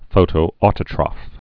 (fōtōtə-trŏf, -trōf)